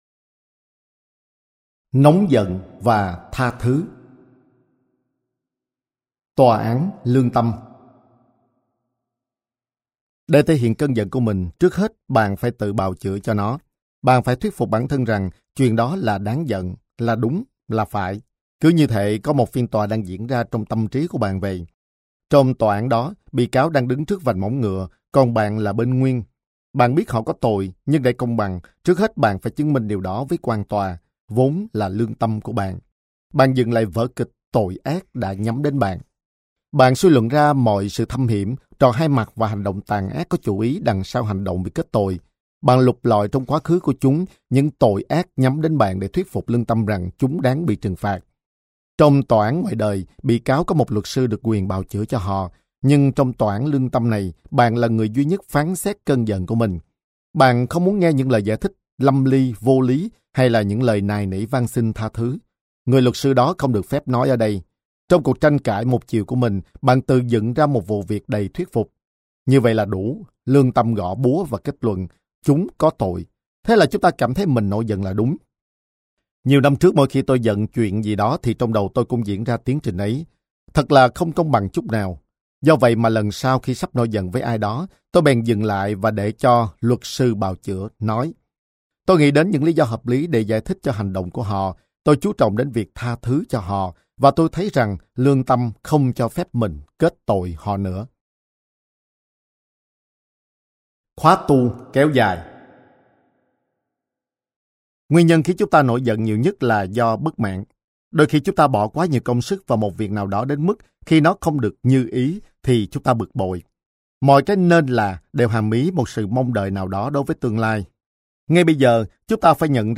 Sách nói Mở cửa trái tim - Hạt Giống Tâm Hồn - Sách Nói Online Hay
THƯ VIỆN SÁCH NÓI HƯỚNG DƯƠNG DÀNH CHO NGƯỜI MÙ THỰC HIỆN